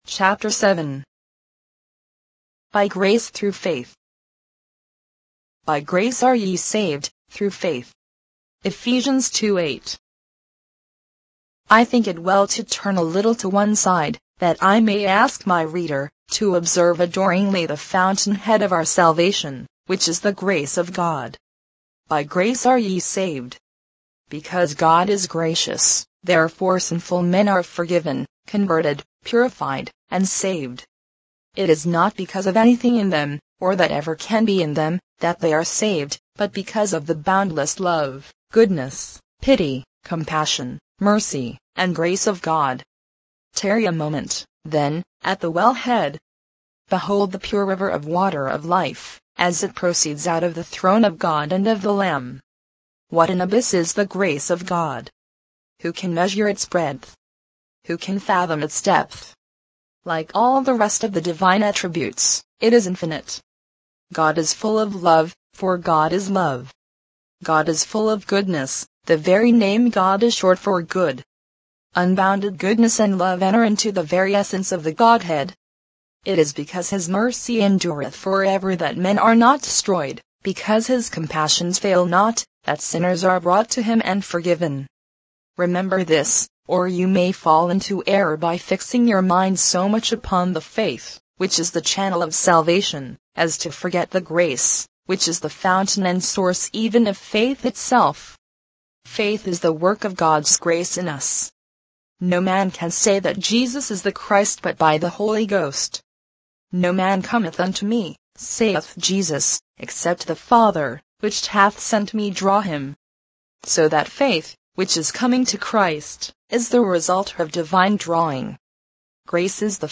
Free Christian Audio Books
Digital Narration for the 21st Century
All of Grace by Charles Spurgeon in mp3 audio -  32kbps mono